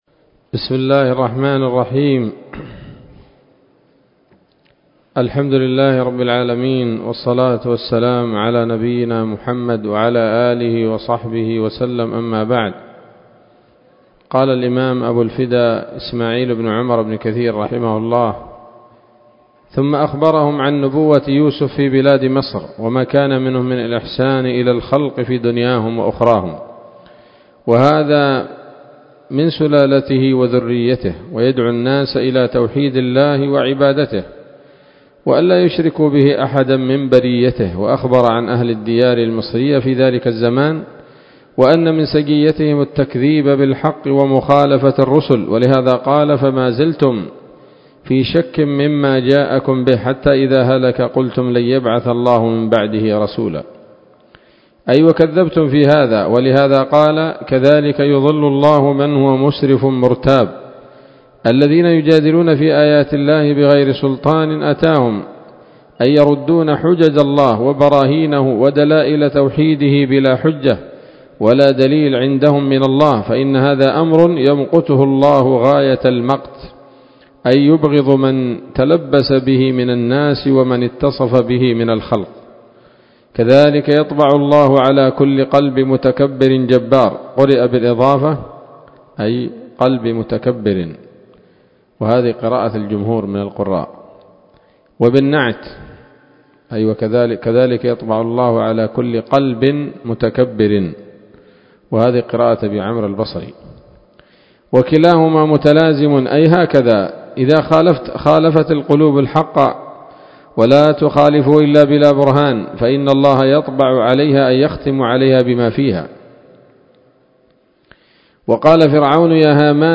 ‌‌الدرس التسعون من قصص الأنبياء لابن كثير رحمه الله تعالى